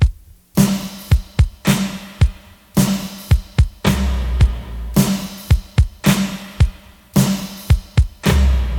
• 109 Bpm 80's Punk Drum Loop Sample C# Key.wav
Free breakbeat sample - kick tuned to the C# note. Loudest frequency: 1161Hz
109-bpm-80s-punk-drum-loop-sample-c-sharp-key-HNU.wav